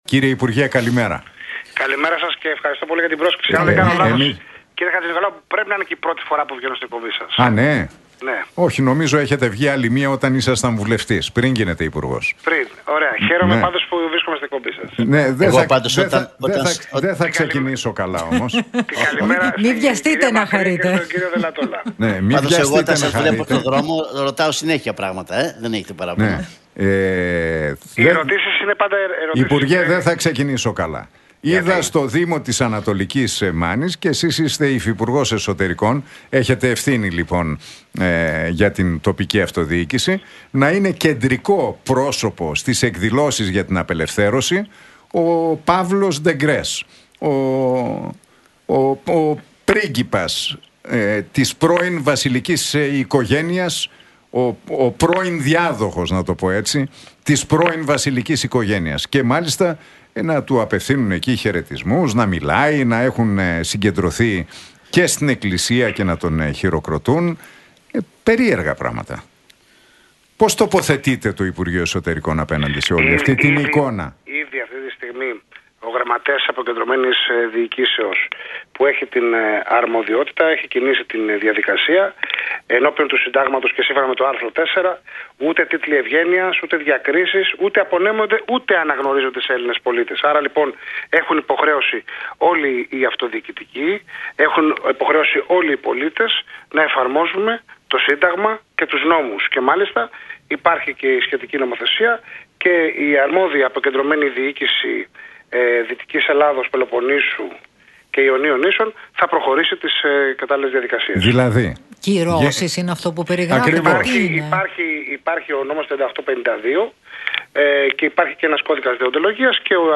Σπανάκης στον Realfm 97,8: Θα κριθούμε για το αποτέλεσμα στο τέλος της θητείας μας